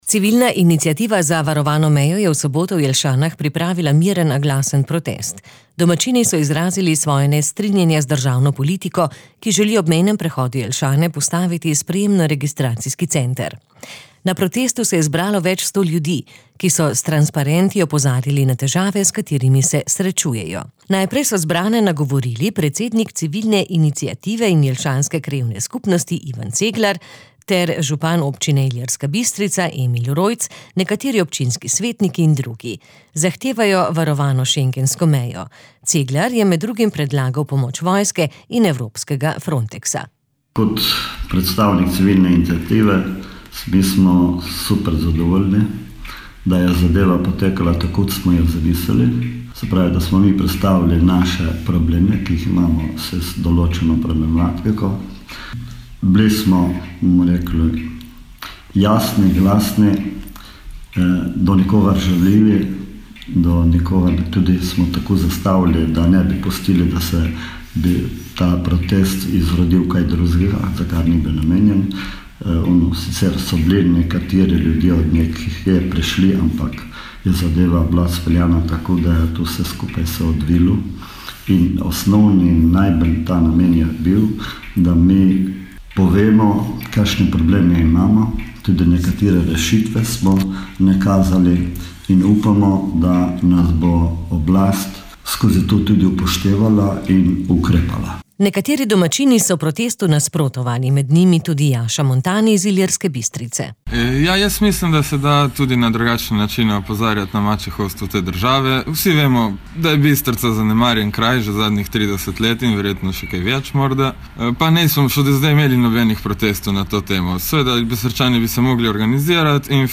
Civilna iniciativa za varovano mejo je v soboto v Jelšanah pripravila miren, a glasen protest. Domačini so izrazili svoje nestrinjanje z državno politiko, ki želi ob mejnem prehodu Jelšane postaviti sprejemno-registracijski center.
p334-89-protest-v-jelsanah.mp3